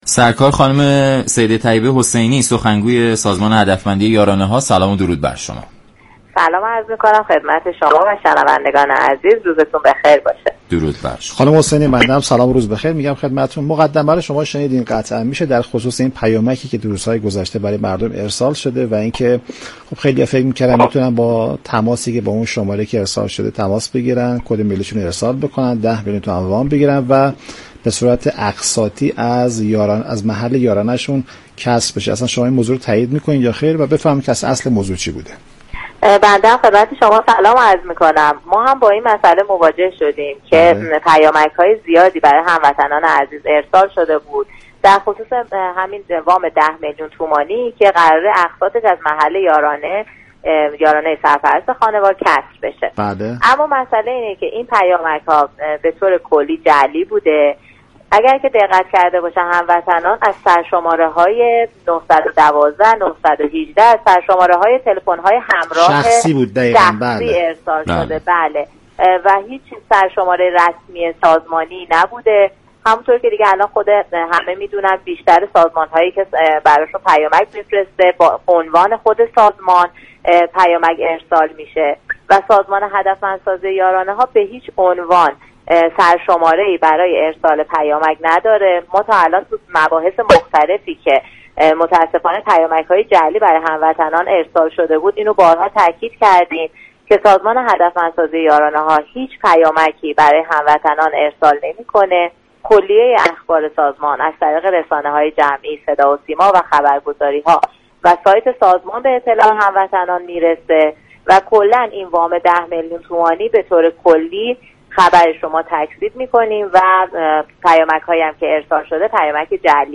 برنامه «نمودار» شنبه تا چهارشنبه هر هفته 10:20 از رادیو ایران پخش می شود.